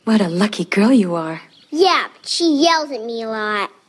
It comes from Disney’s English-language soundtrack to Hayao Miyazaki’s animated masterpiece Tonari no Totoro となりのトトロ ‘My Neighbor Totoro’.
Here is Yeah but she, first in the original, then compressed, then compressed and stretched:
There we can perceive more clearly the pop as the speaker’s lips separate for the b of but. There’s no following vowel to speak of, but then the speaker’s tongue forms the t which is released directly into the sh of she, creating something more like b’che (in phonetic transcription, btʃi or ptʃi).
I’m pretty sure that it’s the habitual present tense yells, although its final consonant is weak and not very sibilant.
Lastly, the word lot in the clip provides an excellent example of a final stop consonant ‘without audible release’.